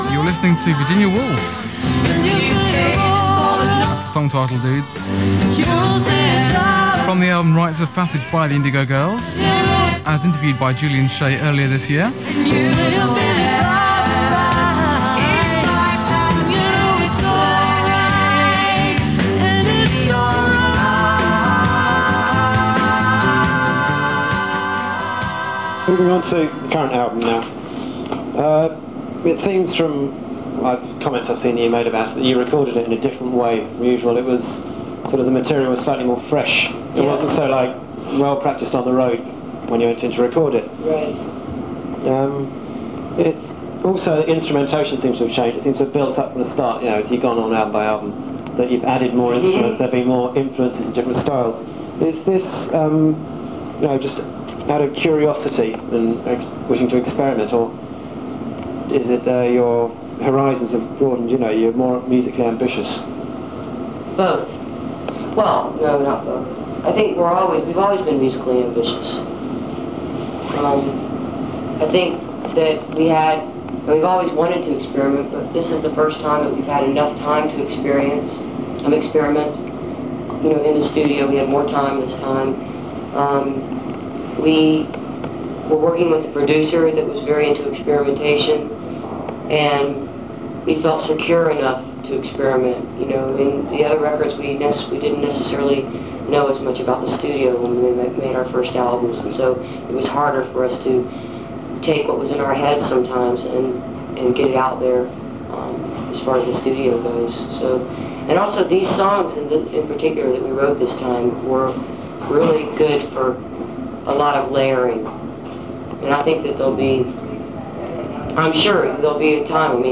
lifeblood: bootlegs: 1992-xx-xx: university radio - nottingham, england
06. interview (2:38)